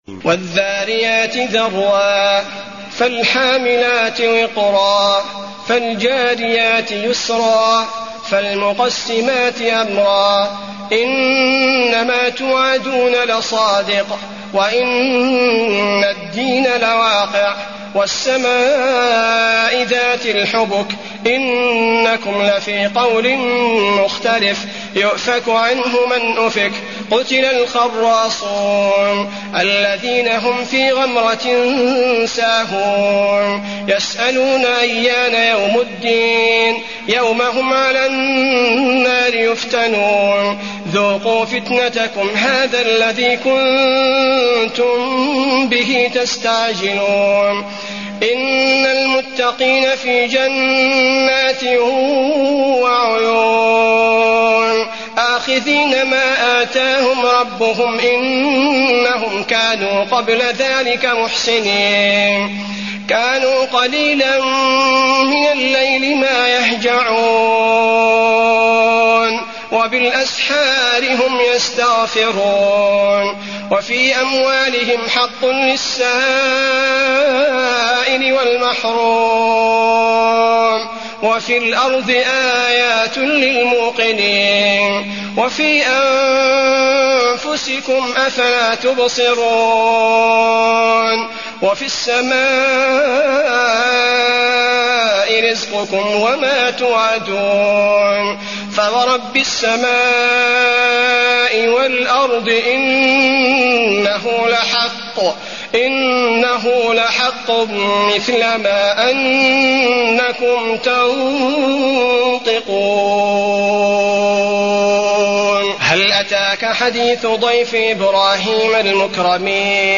المكان: المسجد النبوي الذاريات